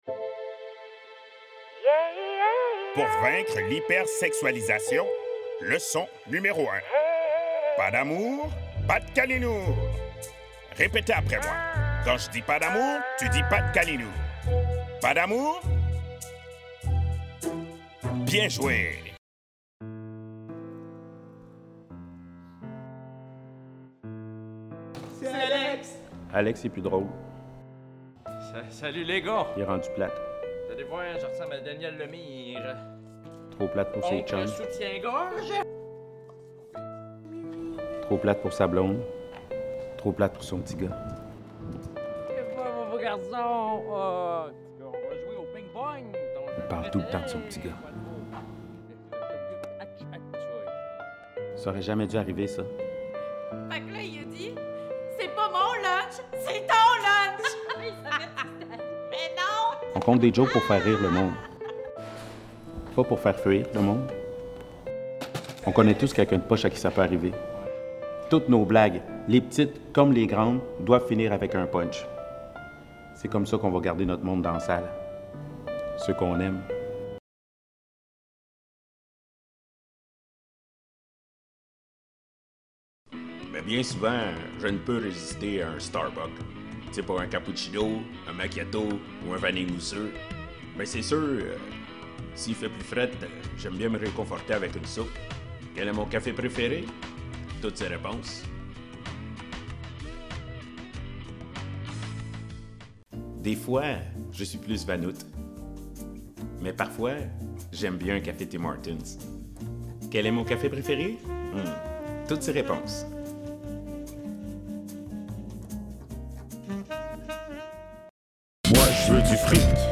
Comédien et humoriste
Langue(s) français, anglais (accent) & créole
Voix de personnage – Démo complet
composition de personnage, variée, touche d’humour